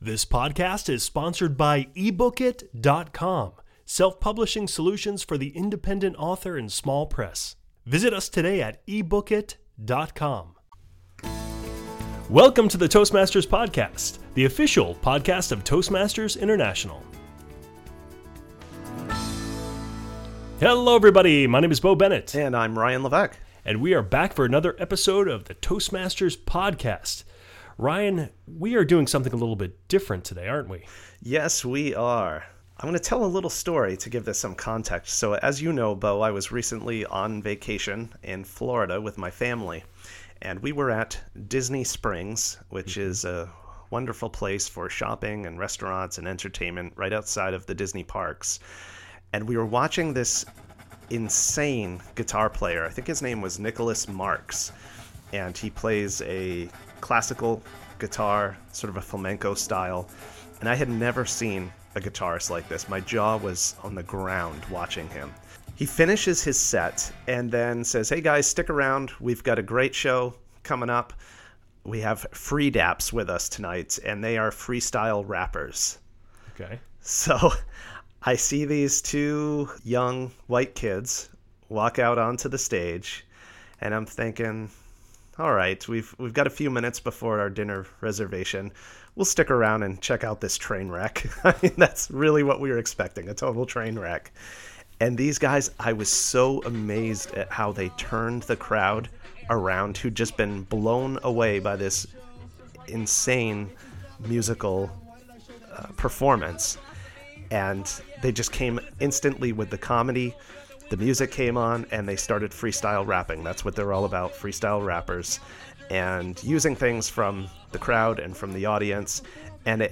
Be sure to listen to the entire podcast to hear an impromptu Toastmasters-inspired freestyle rap!